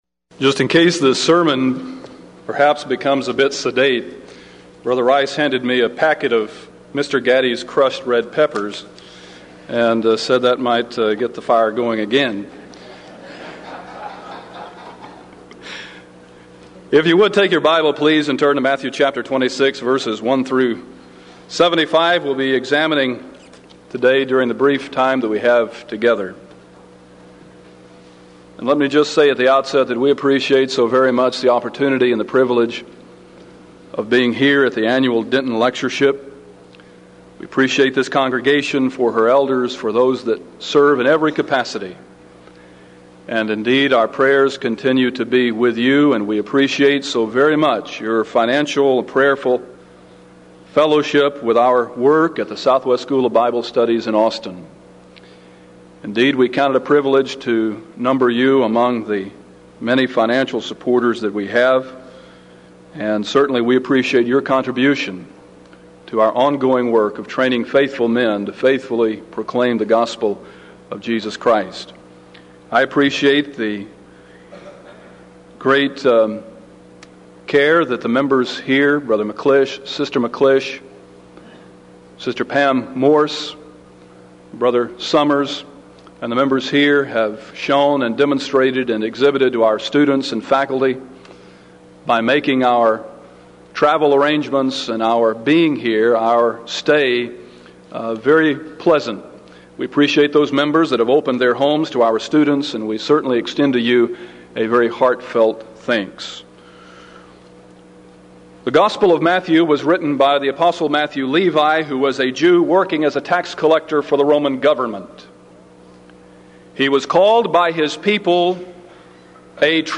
Event: 1995 Denton Lectures Theme/Title: Studies In Matthew